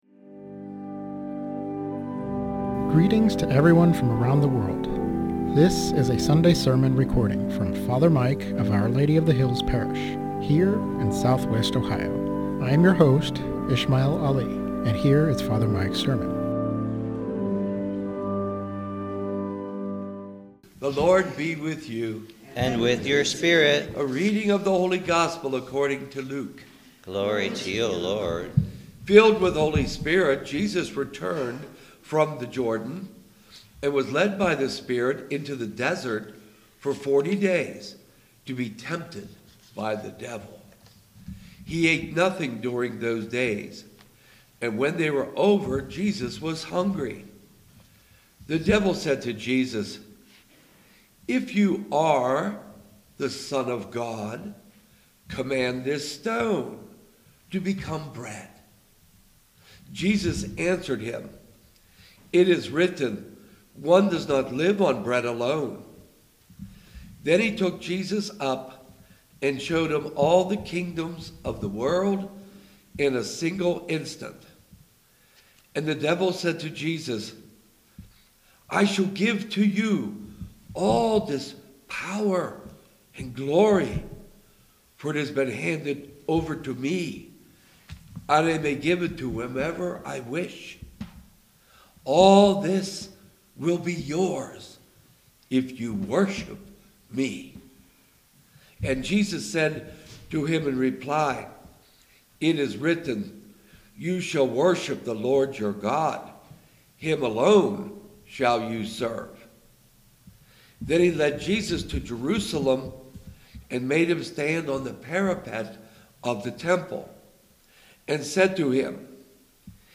Sermon on Luke 4:1-13 - Our Lady of the Hills - Church